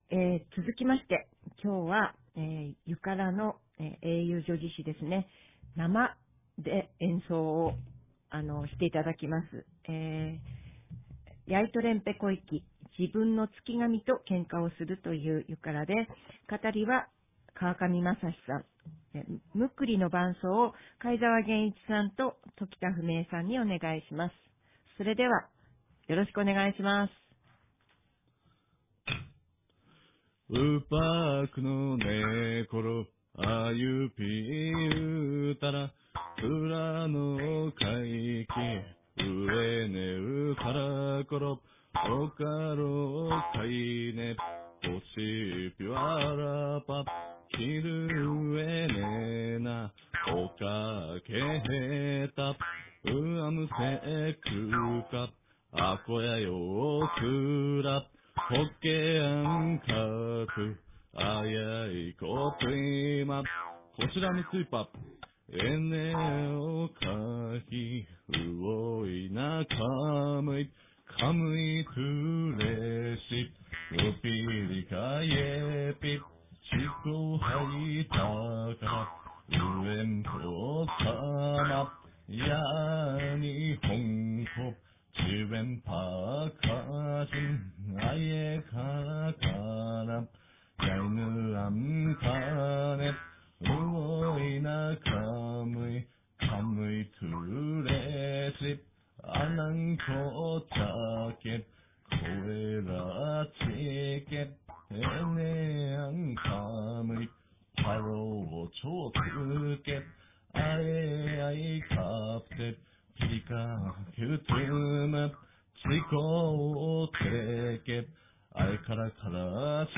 ■ユカﾗ（英雄叙事詩）の口演
ムックリ演奏